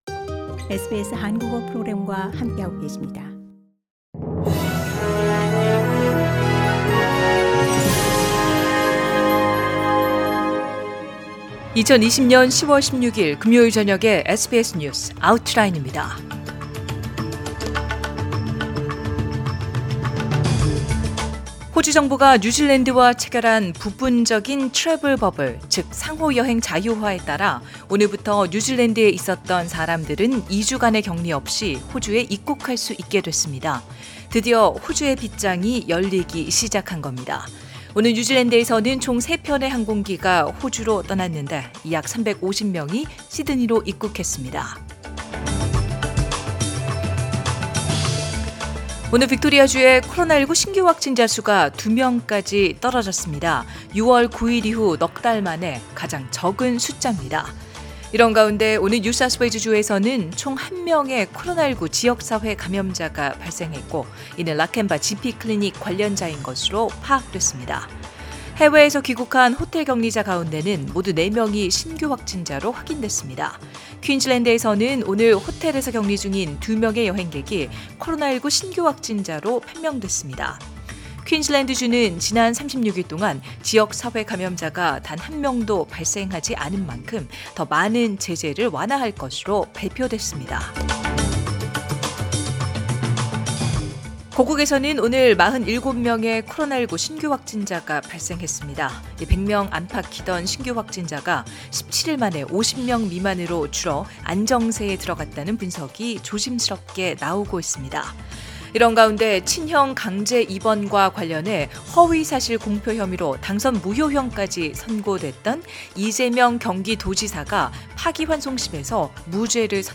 SBS News Outlines...2020년 10월 16일 저녁 주요 뉴스